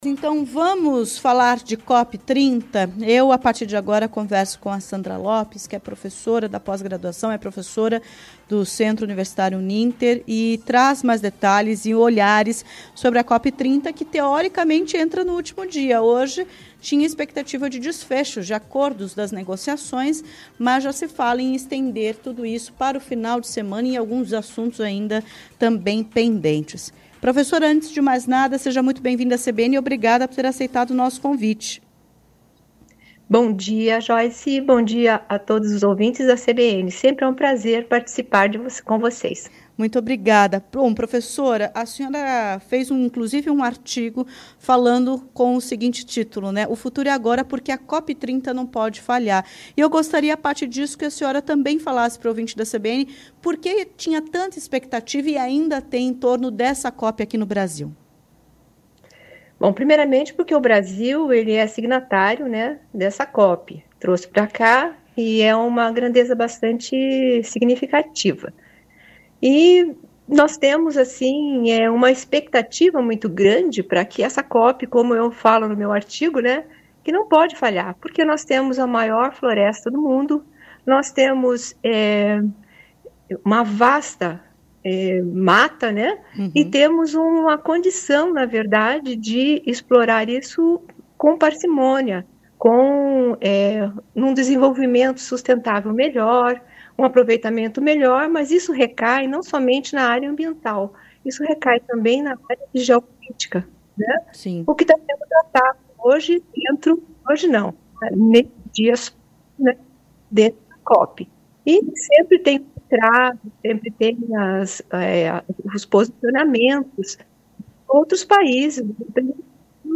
A COP 30 pode ter resultado positivo? Especialista paranaense responde